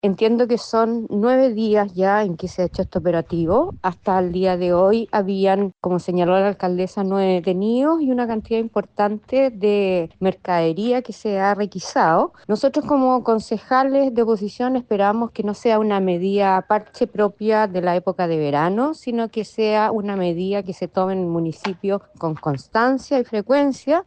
Por su parte, Antonella Pecchenino, edil de la comuna y militante del Partido Republicano, recalcó que esperan que no sea una medida parche propia de la época de verano, sino que una que se tome en el municipio con constancia y frecuencia.